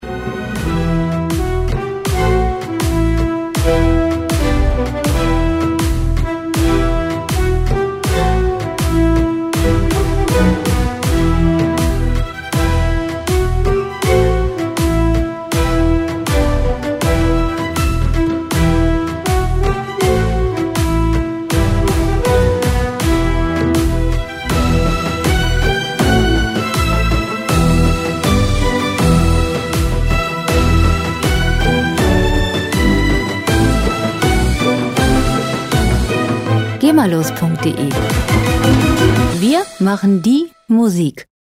Klassik Pop
Musikstil: Classical Crossover
Tempo: 80 bpm
Tonart: E-Moll
Charakter: feurig, energisch
Instrumentierung: Orchester, Synthesizer